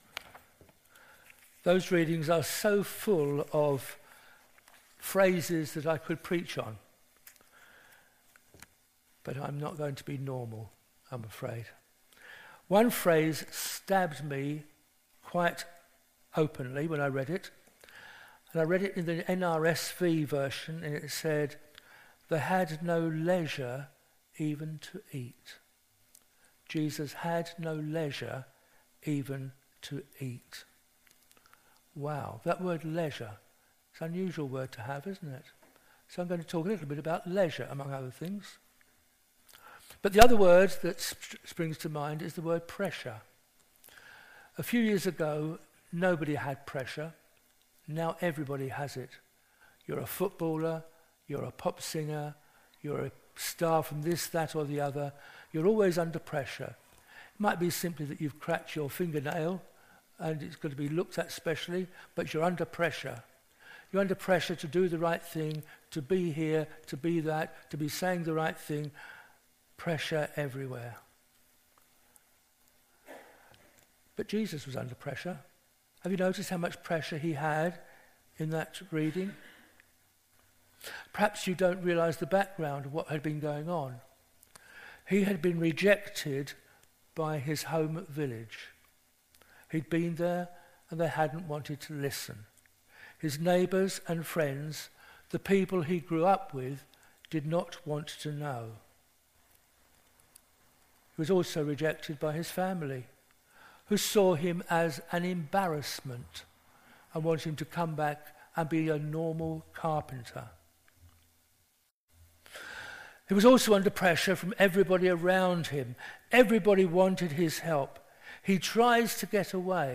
An audio file of the sermon is also available.
07-21-sermon.mp3